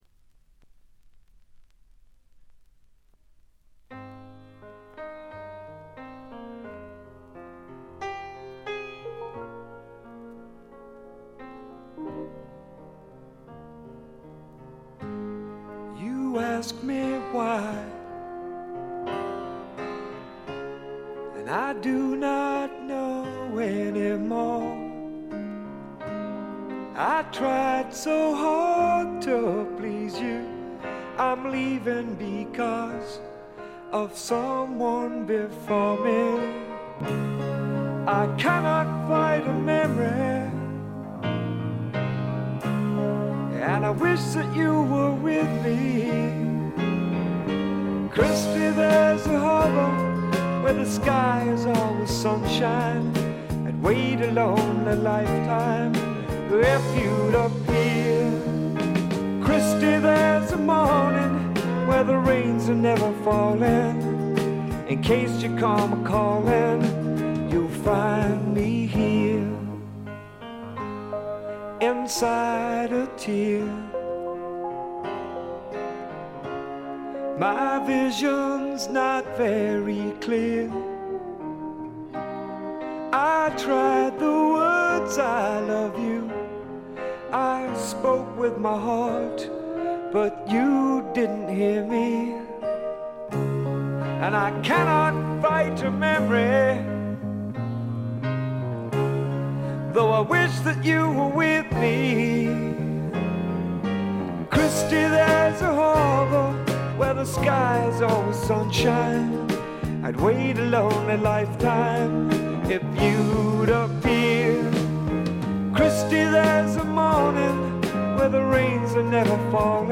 ほとんどノイズ感なし。
ピアノ系AOR系シンガー・ソングライターがお好きな方に大推薦です！
試聴曲は現品からの取り込み音源です。